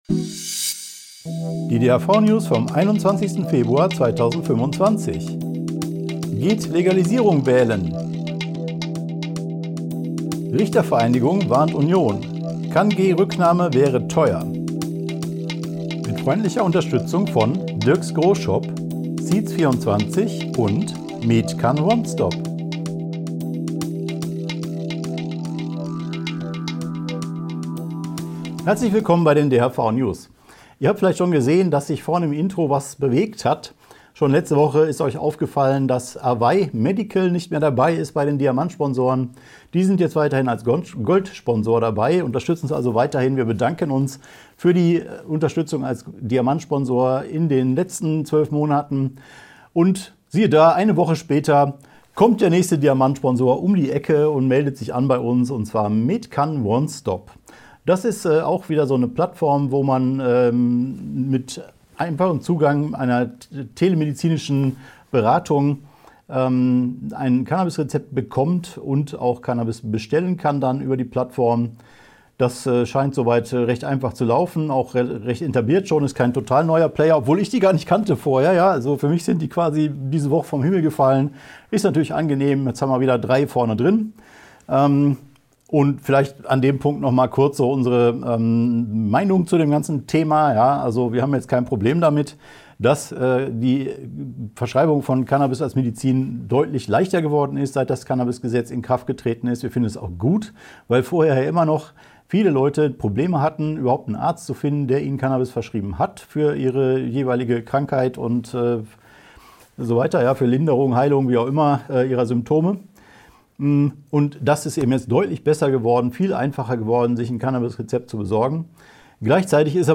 | DHV-News # 457 Die Hanfverband-Videonews vom 21.02.2025 Die Tonspur der Sendung steht als Audio-Podcast am Ende dieser Nachricht zum downloaden oder direkt hören zur Verfügung.